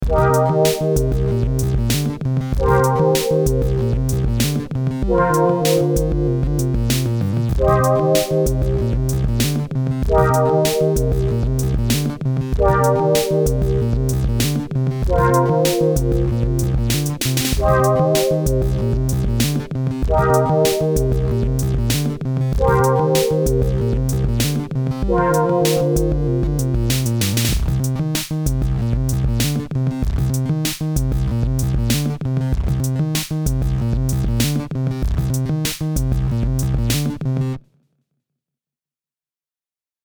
Just made a quick beat to test out uploading.
Quick Beat.wav Filed under: Instrumental | Comments (1)